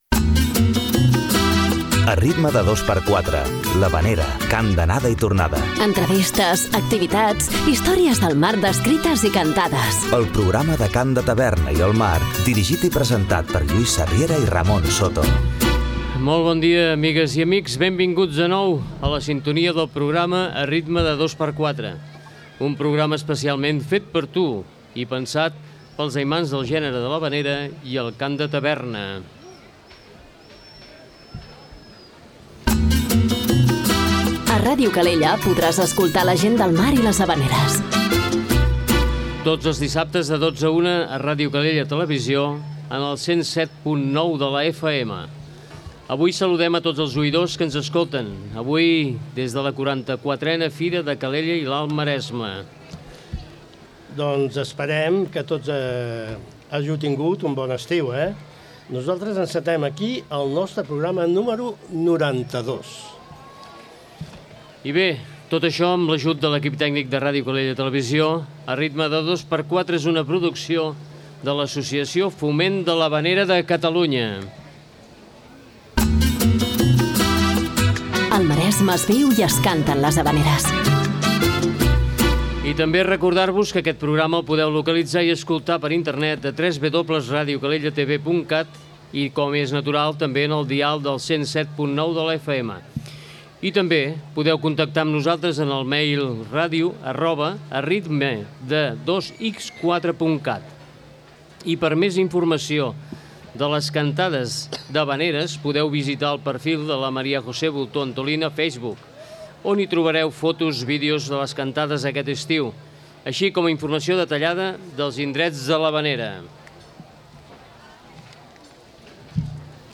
Comença la temporada amb un programa especial enregistrat des de la 44a Fira de Calella i l'Alt Maresme, amb els Faroners de Calella com a convidats.